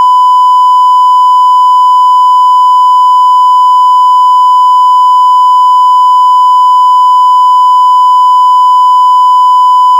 sine-triangle-f32-soundforge.wav